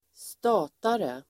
statare substantiv (historiskt), agricultural labourer receiving payment in kind [historical] Uttal: [st'a:tare] Böjningar: stataren, statare, statarna Definition: lantbruksarbetare med dåliga anställningsvillkor